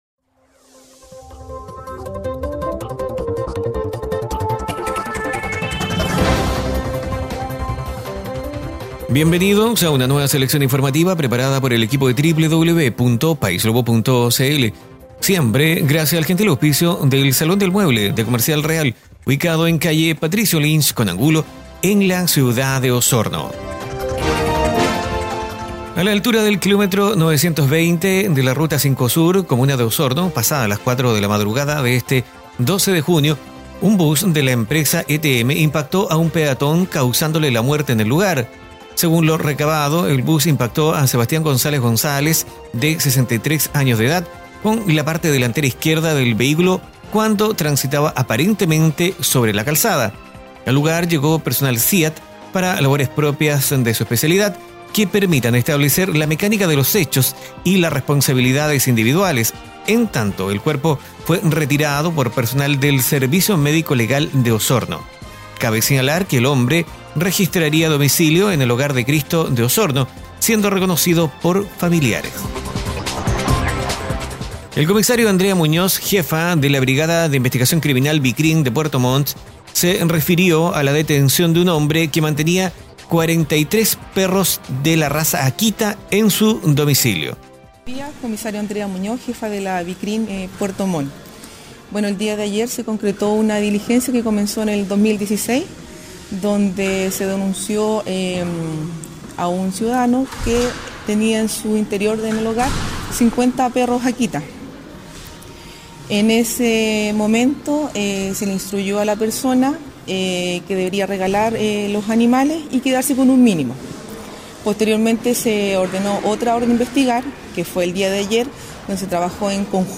12junio2018 Resumen Informativo - Martes